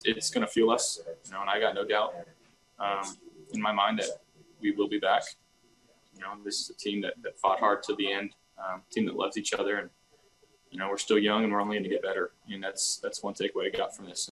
During his postgame interview, Allen assured Buffalo fans that this loss will serve as motivation to come back stronger next season.